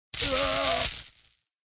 SEnemyShock.ogg